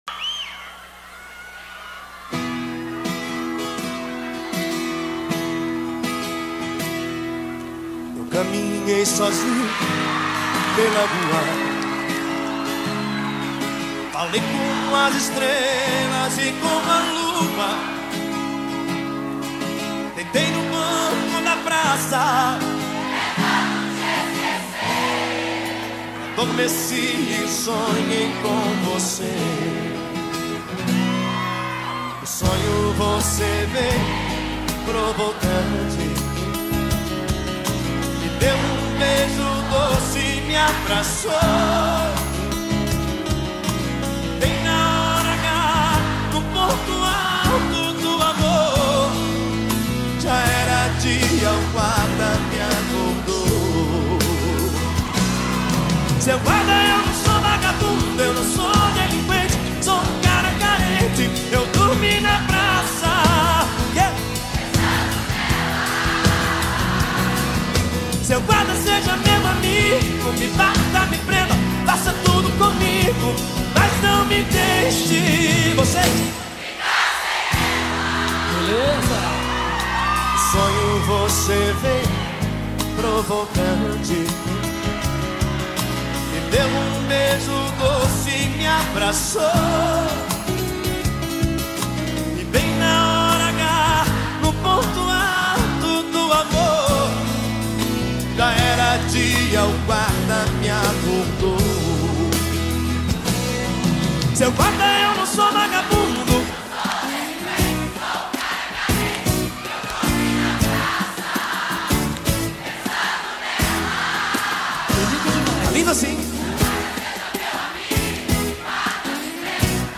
2024-09-14 15:10:22 Gênero: Sertanejo Views